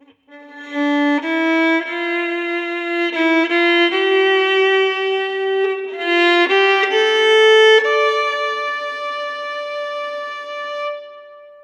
Sad Violin
acoustic cry fiddle melancholy mournful sad solo string sound effect free sound royalty free Sound Effects